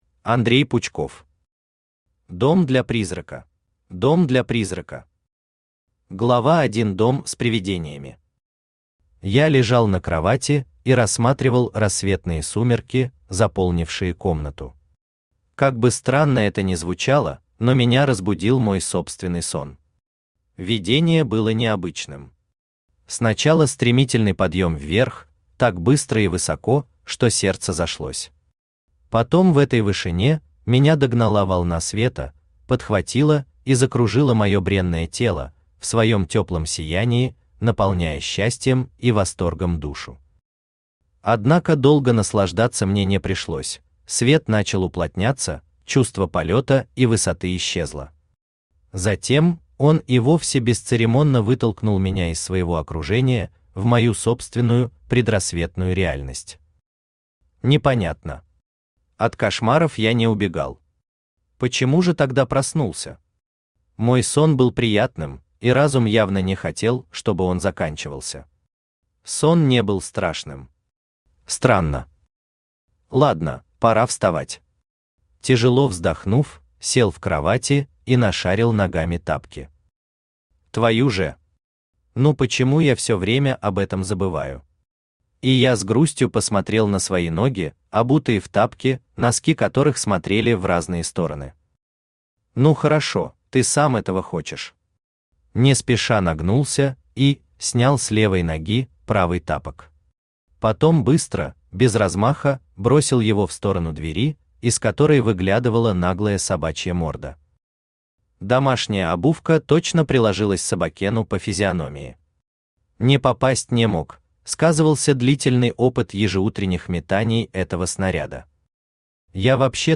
Аудиокнига Дом для призрака | Библиотека аудиокниг
Aудиокнига Дом для призрака Автор Андрей Викторович Пучков Читает аудиокнигу Авточтец ЛитРес.